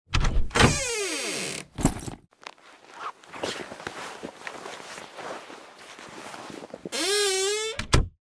wardrobe_2.ogg